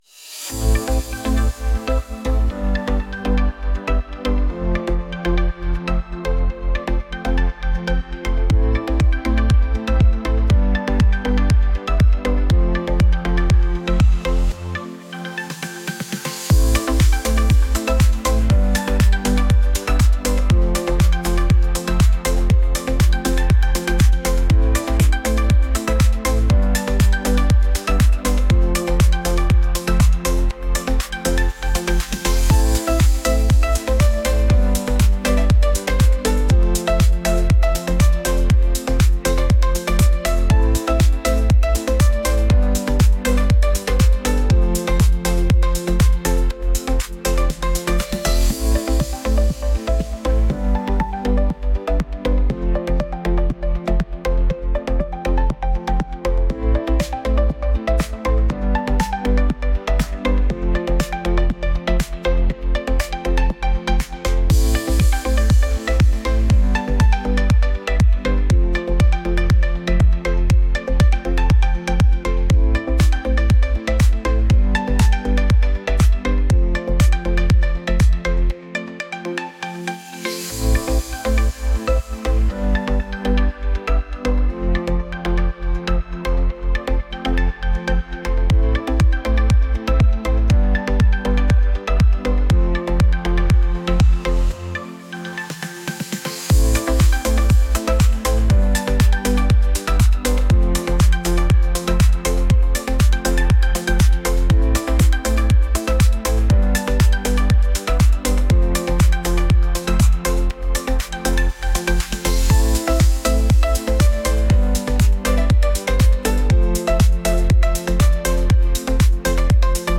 relaxed